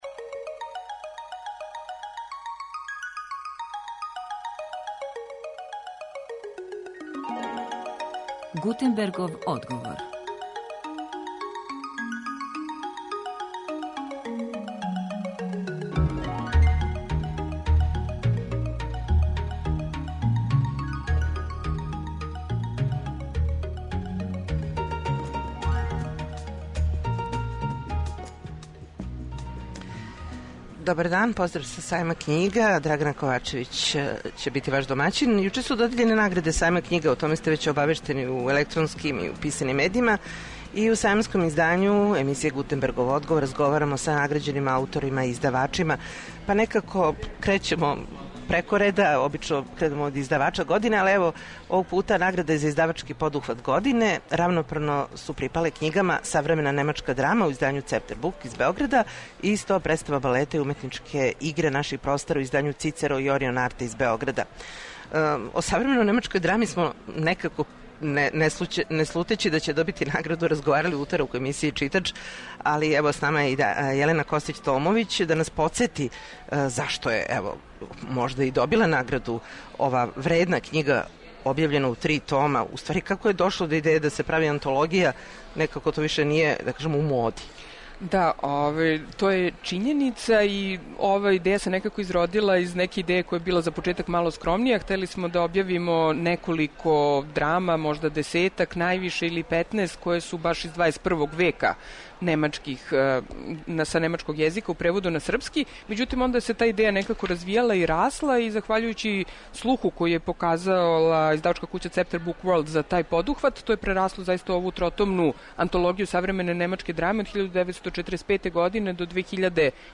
Емисија се данас уживо емитује са 62. Међународног београдског сајма књига.